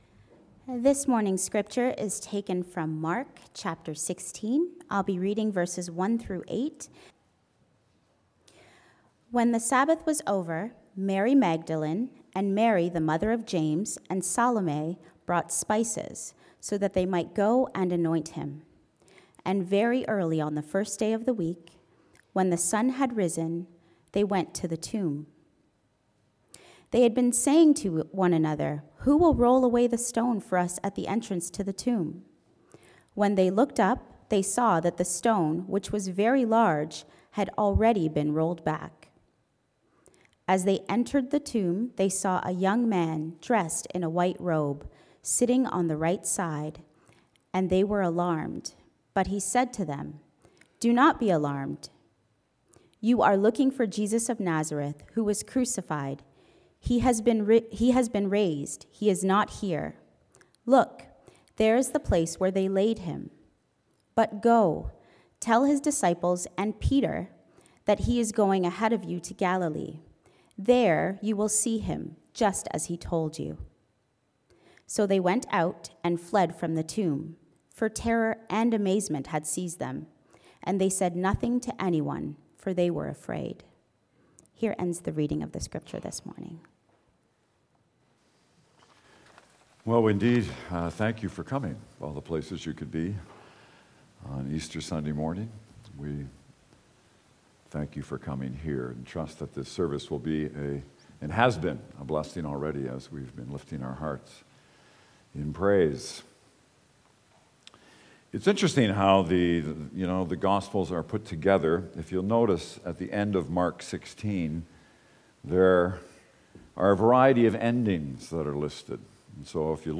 sermon_apr01.mp3